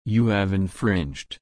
/ɪnˈfɹɪndʒ/